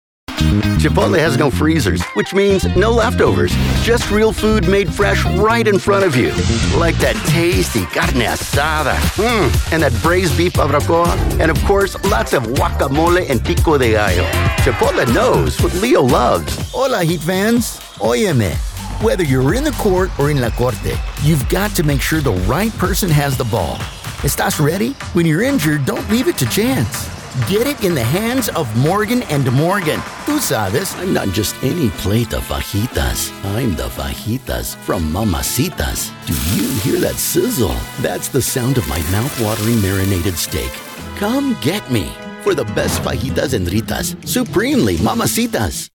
Full-Time, award-winning, bilingual voice actor with a pro studio.
Bilingual Commercial Demo (EN / SP)
Southern, Hispanic, Mexican, Latino, American Standard English
Middle Aged